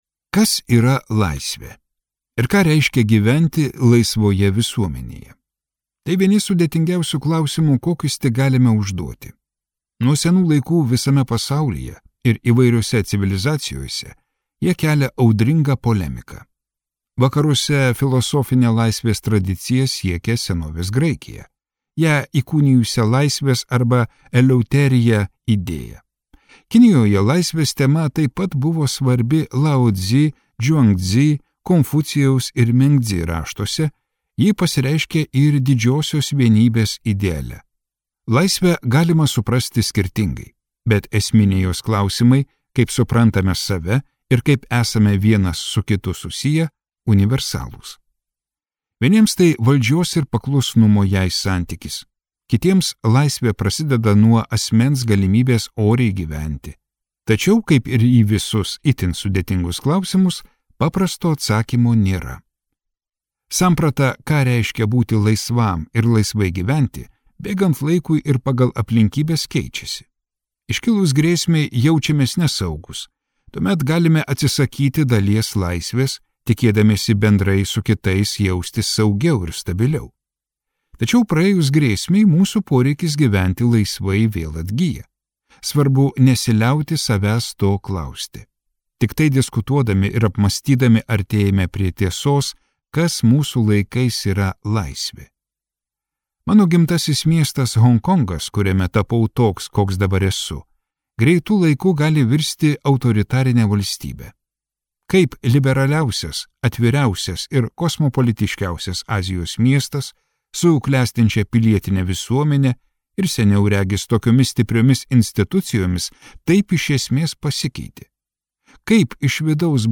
Skaityti ištrauką play 00:00 Share on Facebook Share on Twitter Share on Pinterest Audio Laisvė.